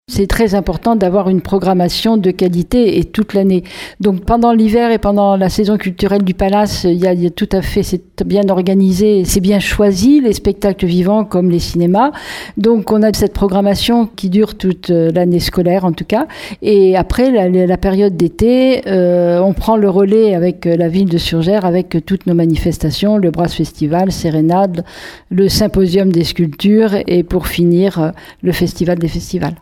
On écoute Catherine Desprez, la maire de Surgères :